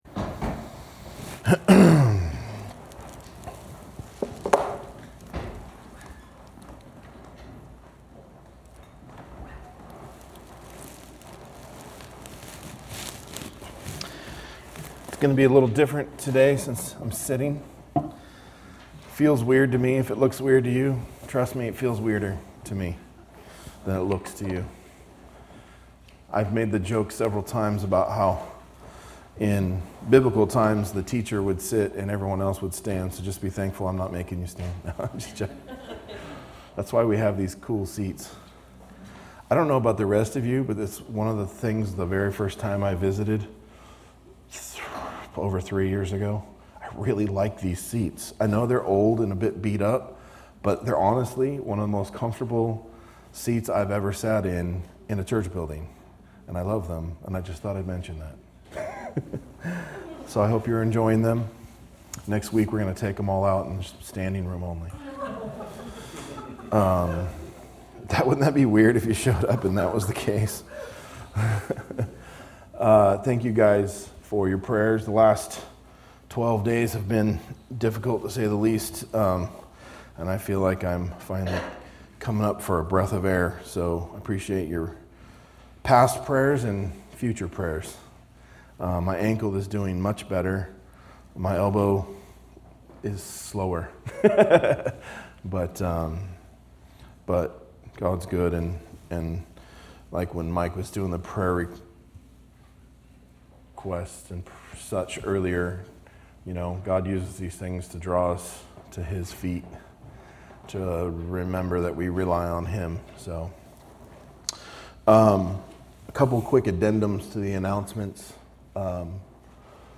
A message from the series "Biblical Worship Series." We worship God outwardly by having and demonstrating genuine gospel love for the church.